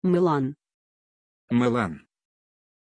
Pronunciation of Mylann
pronunciation-mylann-ru.mp3